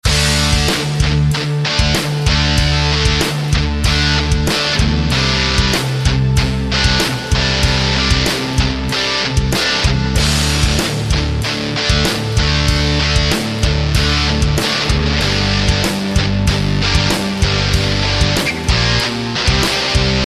Ezen a héten egy egyszerû akkordmenetet veszünk, és megnézzük, hogy hogyan lehet többféle skálát kombinálni a szólójáték során. A kör nyolc ütembõl áll, és két ütemenként vannak akkordváltások: D-D C-C D-D Gm-Gm.
Az utolsó ütemben egy Bb-C lépéssel is befejezhetjük a kört, mint az alapot tartalmazó mp3-ban is hallható.